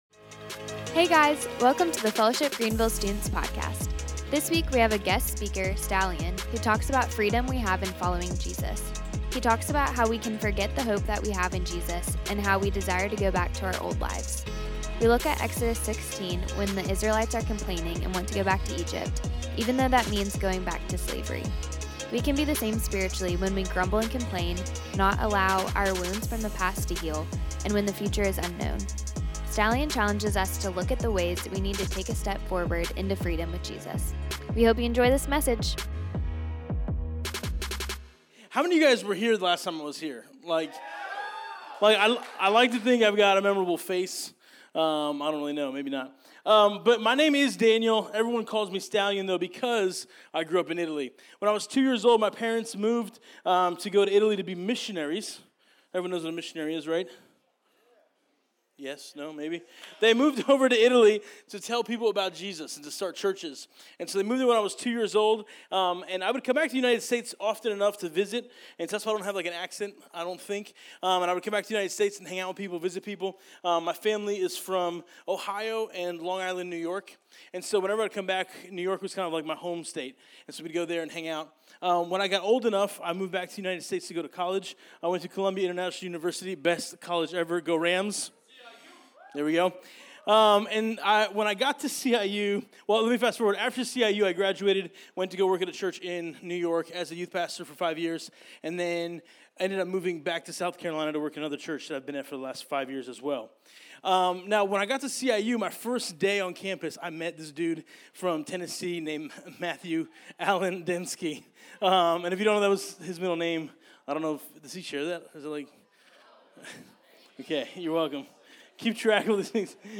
This week we have a guest speaker